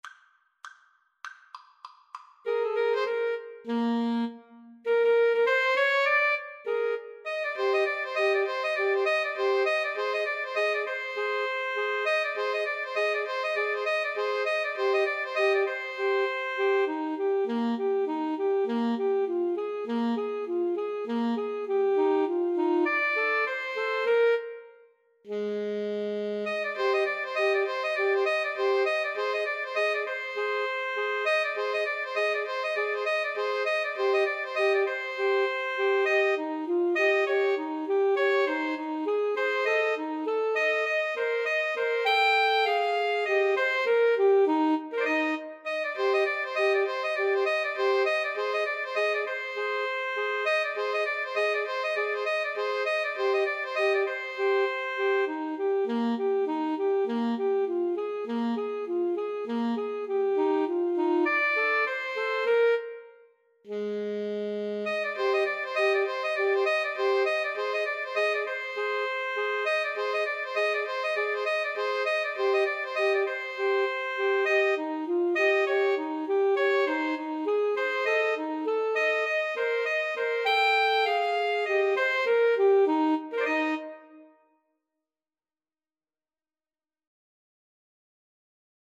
Alto Saxophone 1Alto Saxophone 2Tenor Saxophone
Eb major (Sounding Pitch) Bb major (French Horn in F) (View more Eb major Music for 2-Altos-Tenor-Sax )
Jazz (View more Jazz 2-Altos-Tenor-Sax Music)